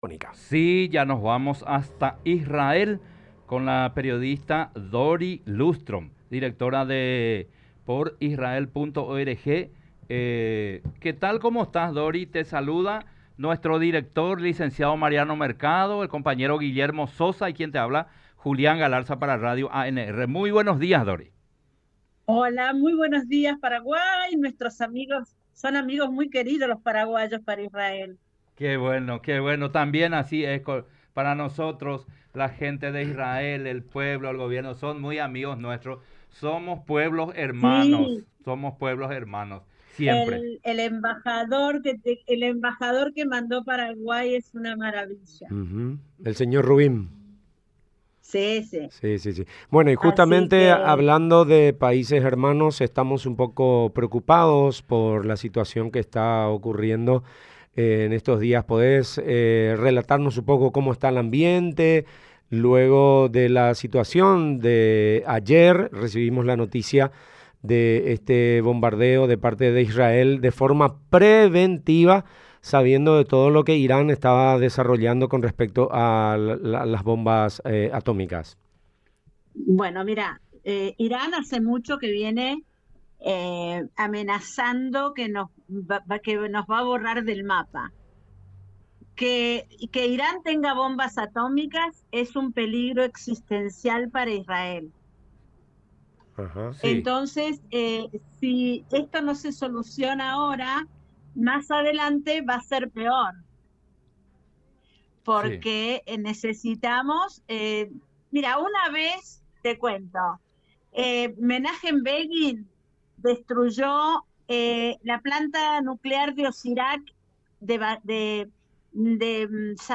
Entrevista ANRNEWS